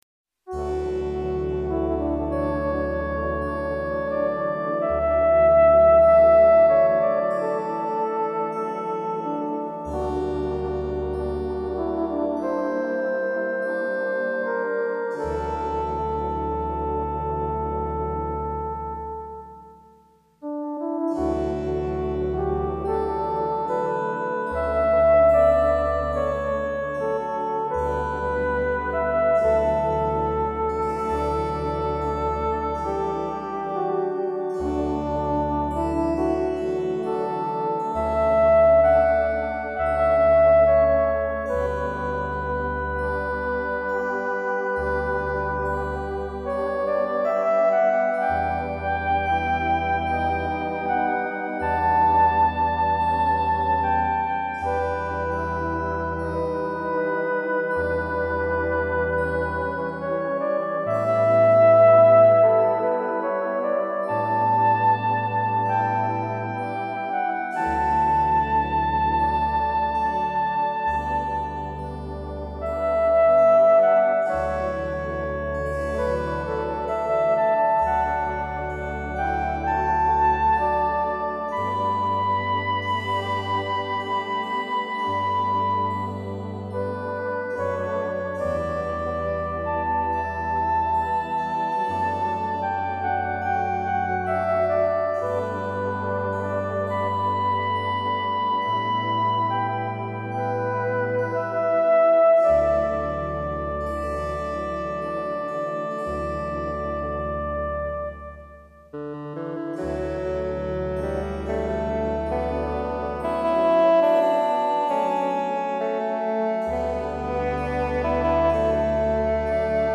Music for relaxation and reflection